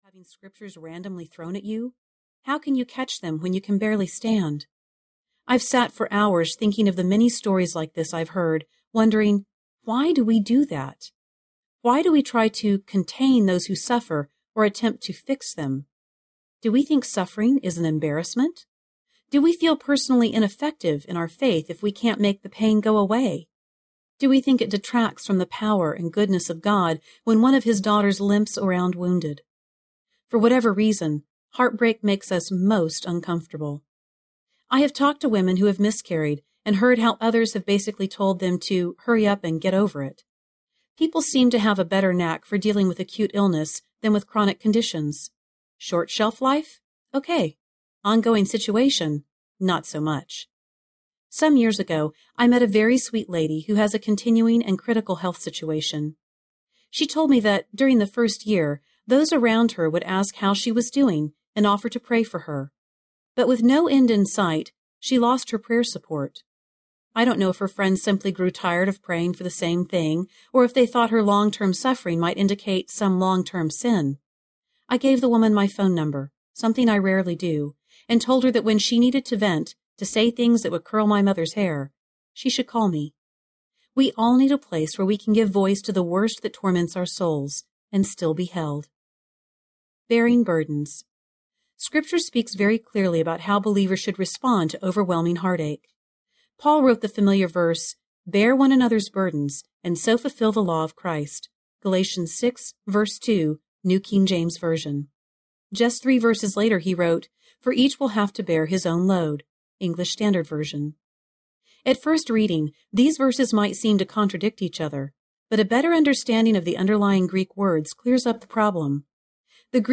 The Storm Inside Audiobook
Narrator
5.9 Hrs. – Unabridged